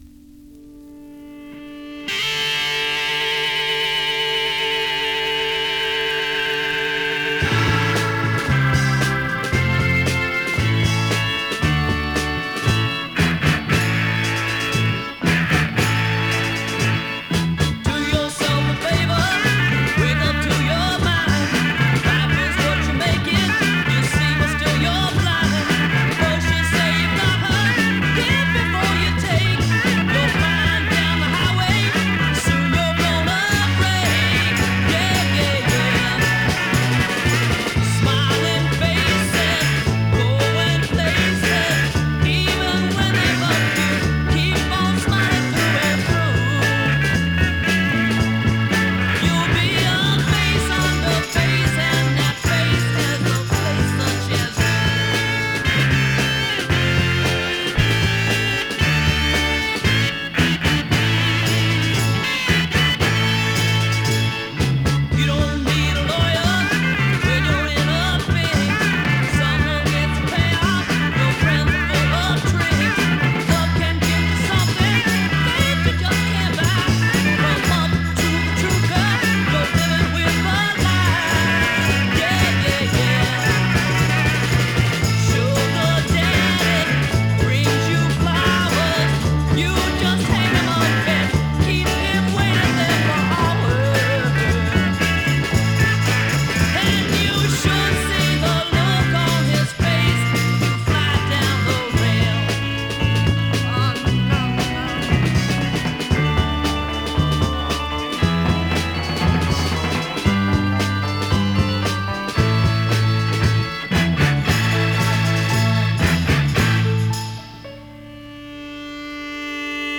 US 45 ORIGINAL 7inch シングル PSYCH FUZZ TRAFFIC 試聴
試聴 (実際の出品物からの録音です)